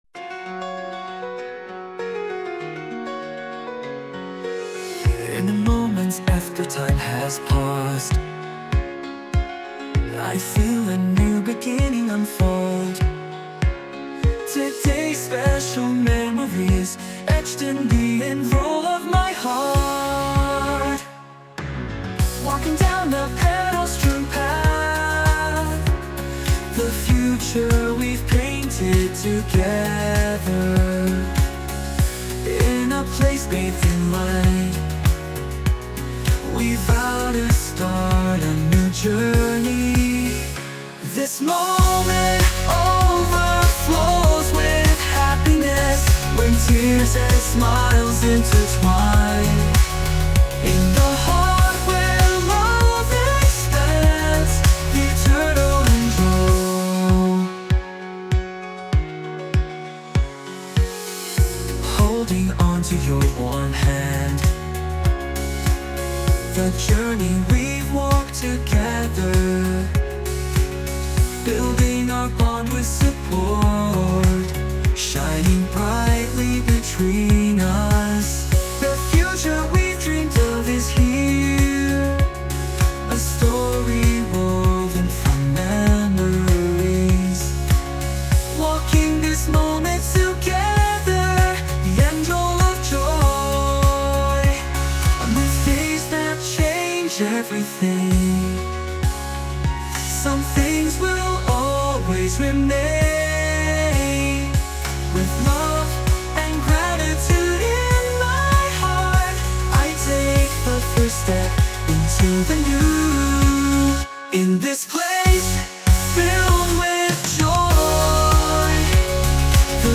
洋楽男性ボーカル著作権フリーBGM ボーカル
男性ボーカル洋楽 男性ボーカルエンドロール
男性ボーカル曲（英語）です。